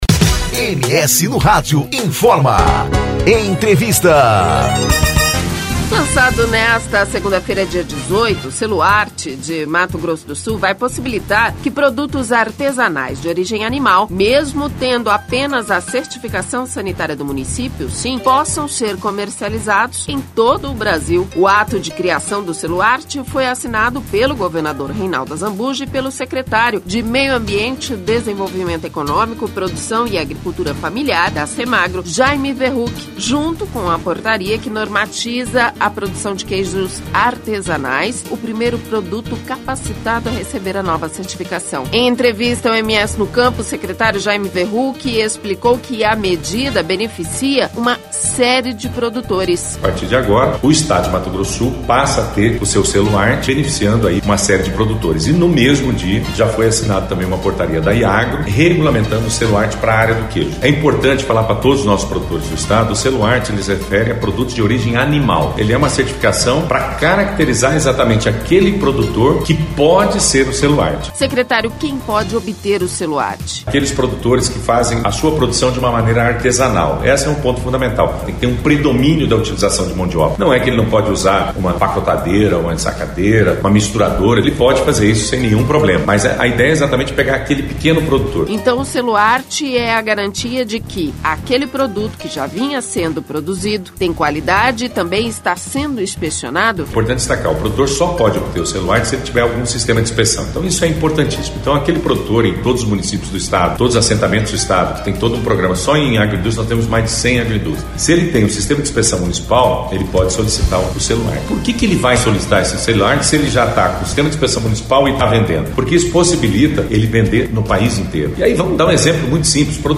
ENTREVISTA: Secretário Jaime Verruck, da Semagro, fala sobre a implantação do Selo Arte
Em entrevista ao MS no Campo, o Secretário Jaime Verruck explicou que a medida beneficia produtores, consumidores, agrega valor e traz identidade para os produtos sul-mato-grossenses.